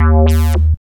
BASS30  02-L.wav